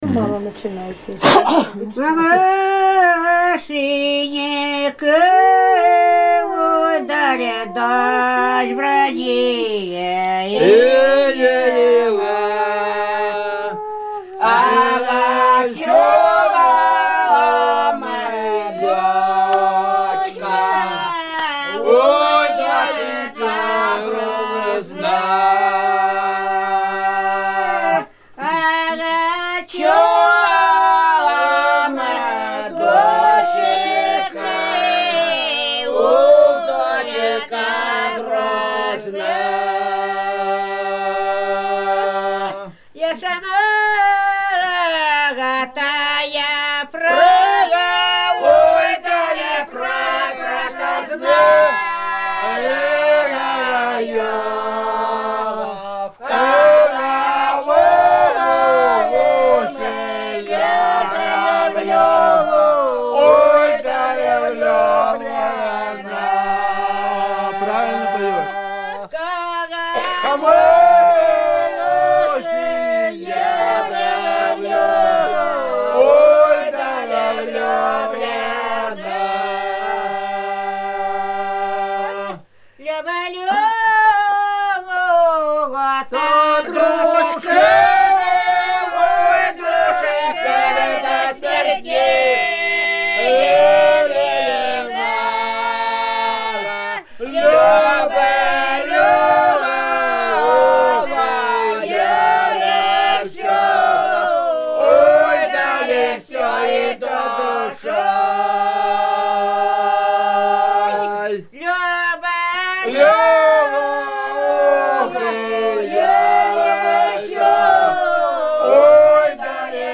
Старинные песни
"Мамашенька дочь бранила" протяжная
с. Кежма, 1993г.